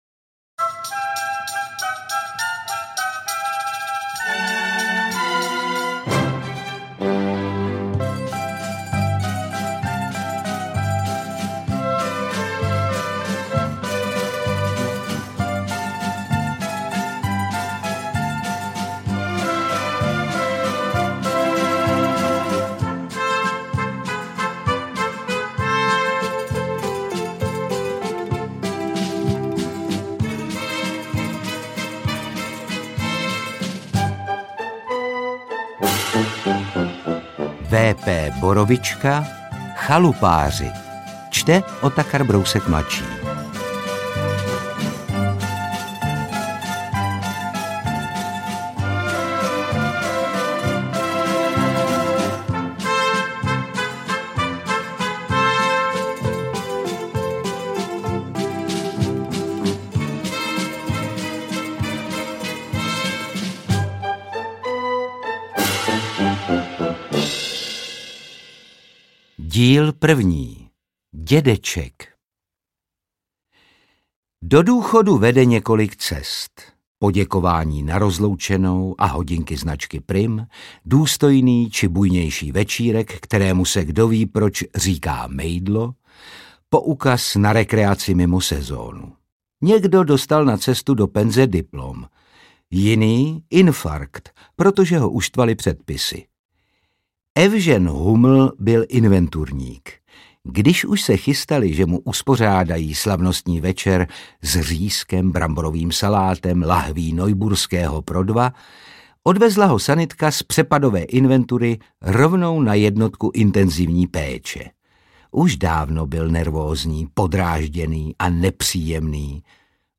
Audiobook
Read: Otakar Brousek Jr.